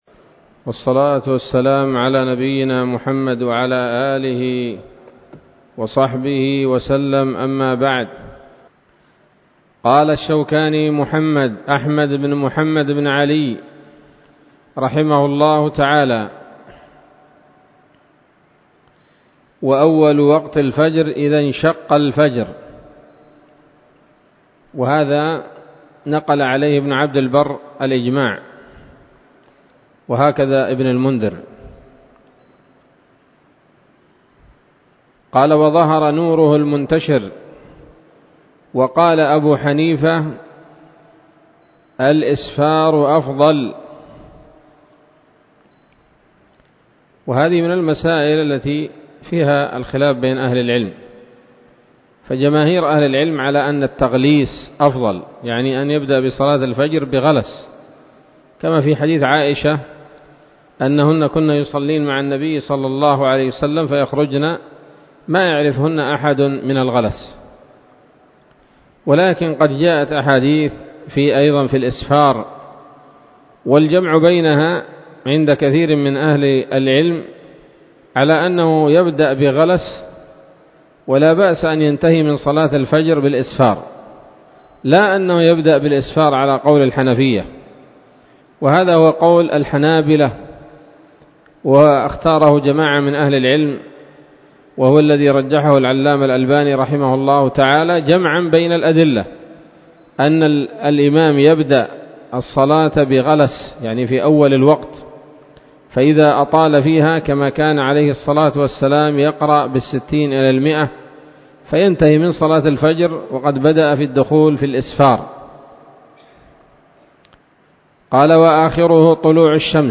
الدرس الثالث من كتاب الصلاة من السموط الذهبية الحاوية للدرر البهية